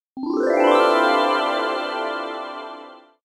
알림음(효과음) + 벨소리
알림음 8_Ascending3.mp3